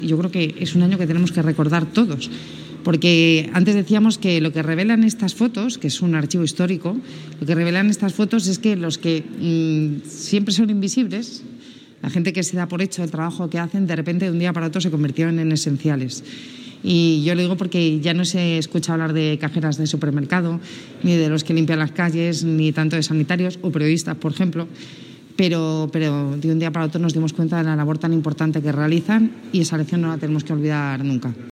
Durante la presentación del libro homenaje 'El latido de Madrid' del que es madrina
Nueva ventana:Declaraciones de la vicealcaldesa de Madrid, Begoña Villacís